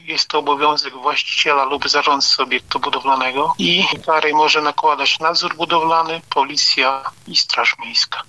Kto jest odpowiedzialny za usunięcie nacieku lodowego mówi Powiatowy Inspektor Nadzoru Budowlanego w Radomiu Jarosław Domański: